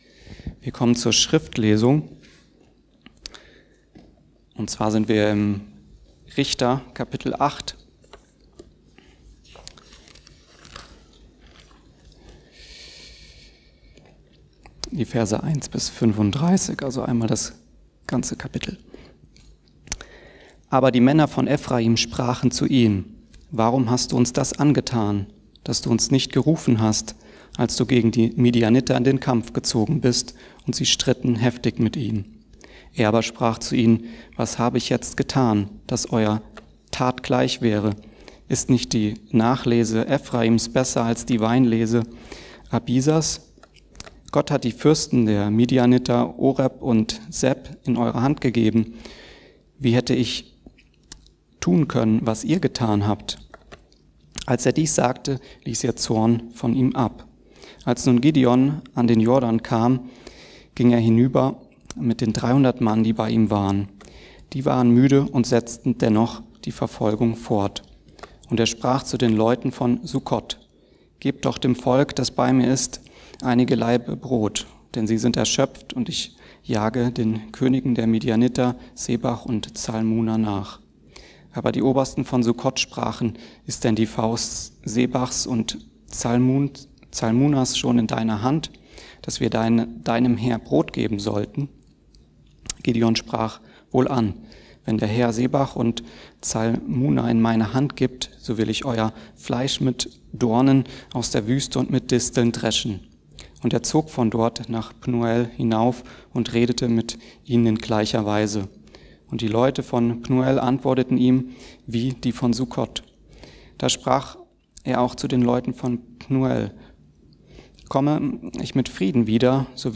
Wer Hat Autorität in Deinem Leben? ~ Mittwochsgottesdienst Podcast